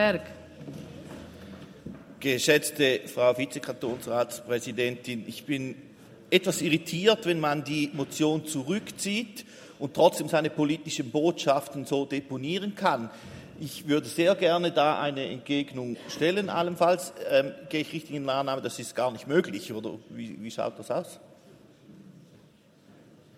28.11.2023Wortmeldung
Session des Kantonsrates vom 27. bis 29. November 2023, Wintersession